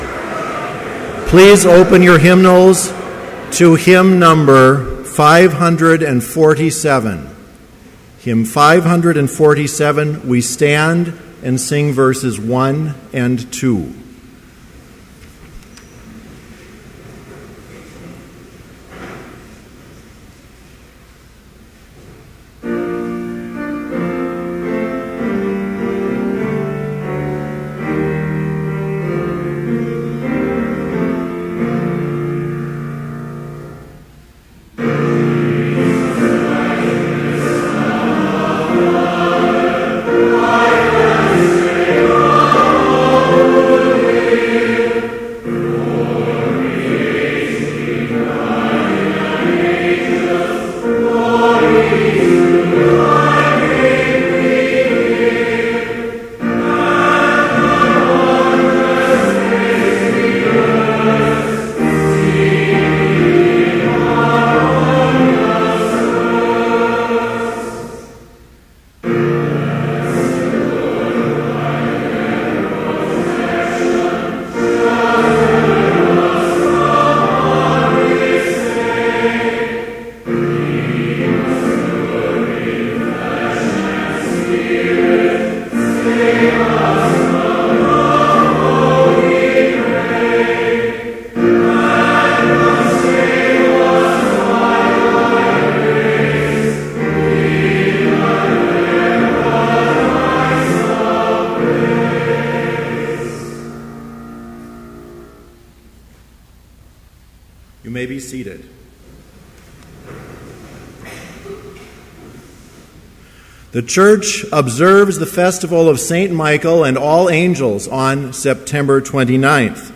Complete service audio for Chapel - September 30, 2013